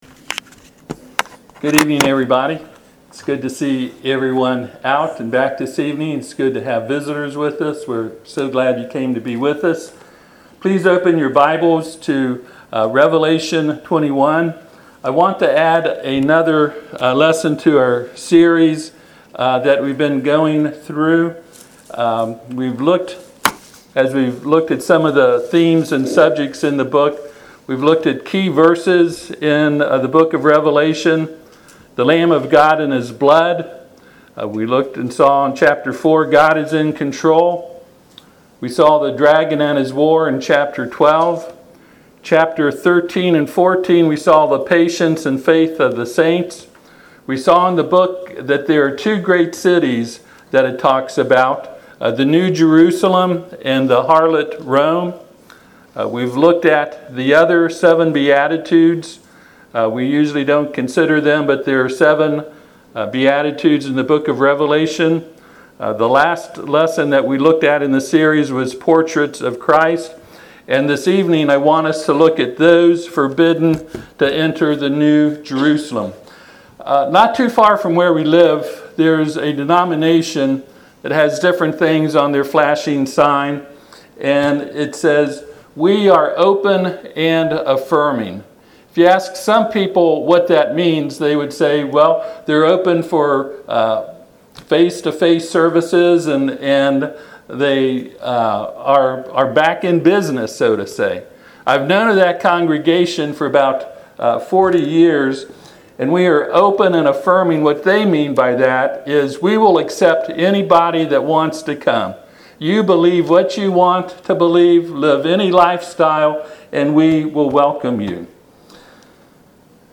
Service Type: Sunday PM Topics: apocalyptic , mark of beast , new earth , new heaven , prophecy